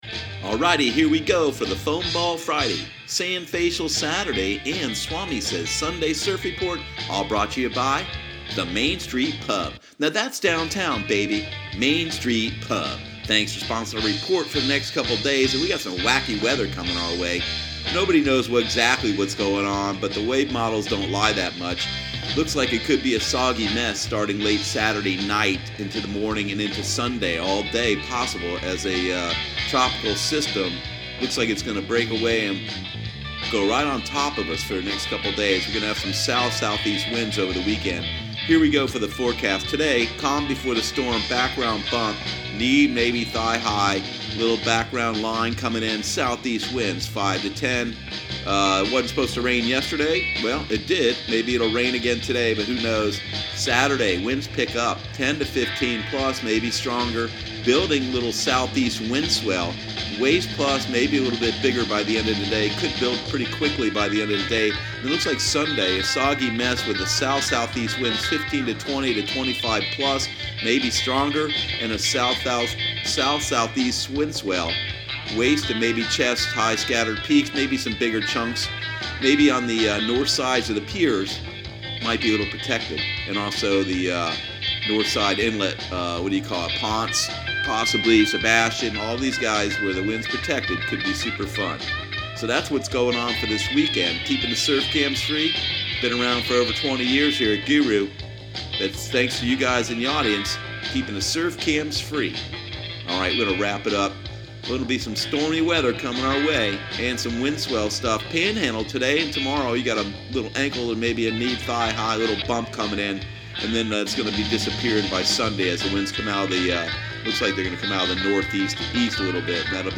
Surf Guru Surf Report and Forecast 05/25/2018 Audio surf report and surf forecast on May 25 for Central Florida and the Southeast.